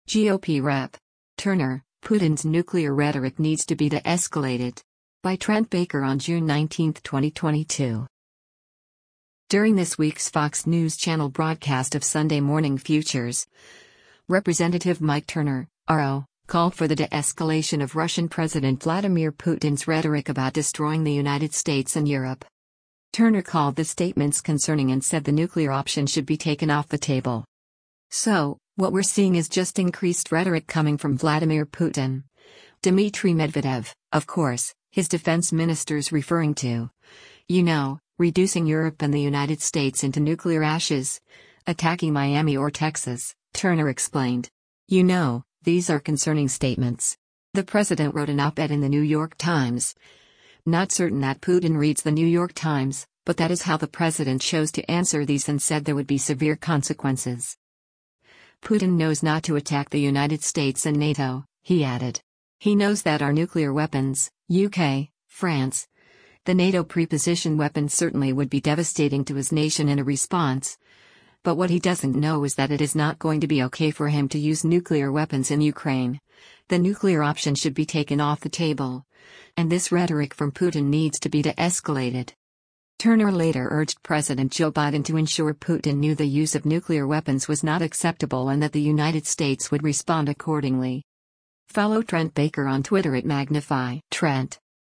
During this week’s Fox News Channel broadcast of “Sunday Morning Futures,” Rep. Mike Turner (R-OH) called for the de-escalation of Russian President Vladimir Putin’s rhetoric about destroying the United States and Europe.